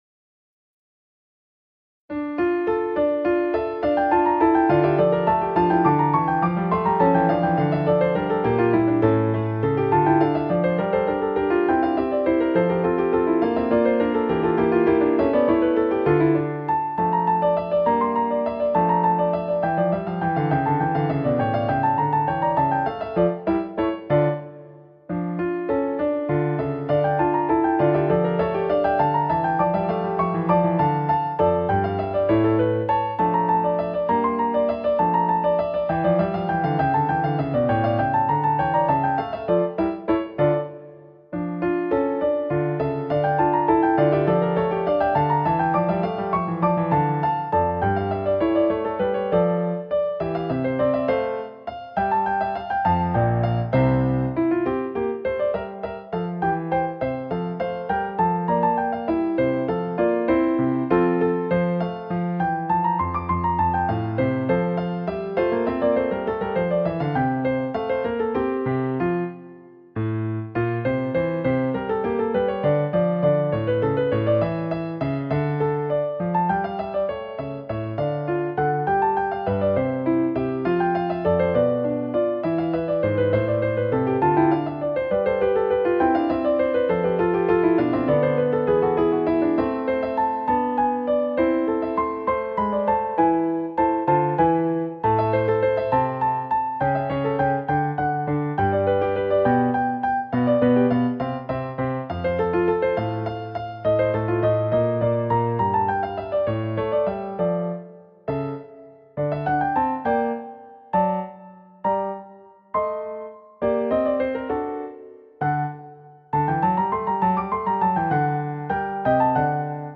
Albinoni Allegro slow